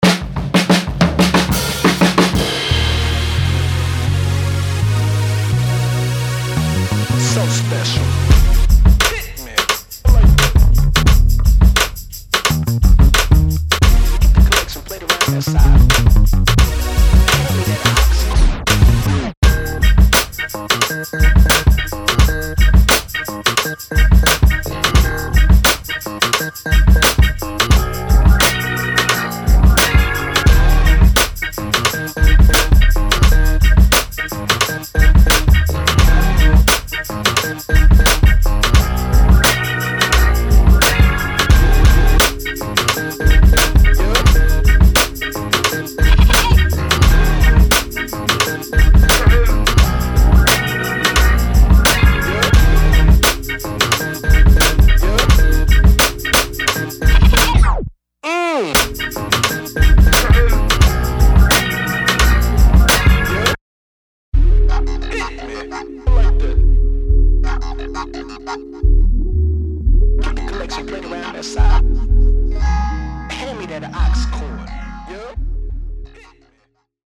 灵魂乐数十年来的声音
键盘，现场吉他，贝斯，萨克斯，小号，复古合成器，更多的灵感来自于灵魂音乐的演变
奔放、摇摆、流畅、紧张的灵魂与其说是一种流派，不如说是一种感觉